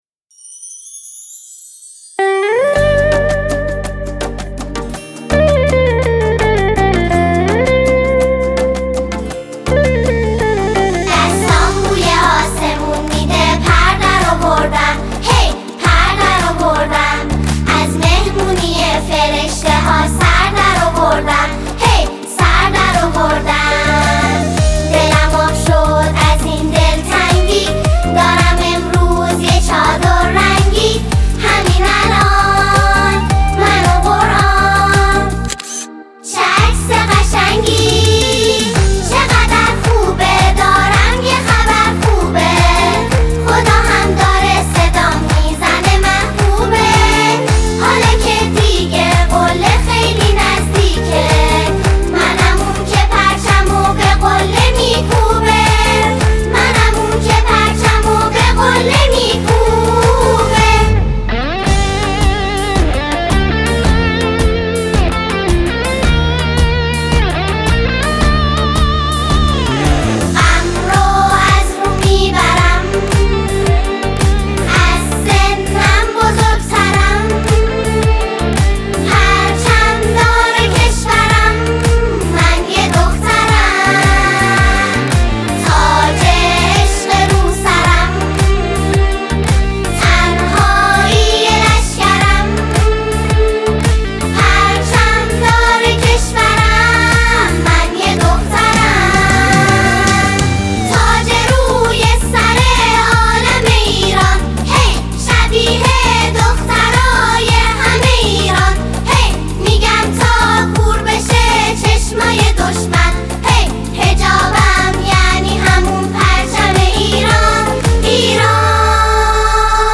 نماهنگ مذهبی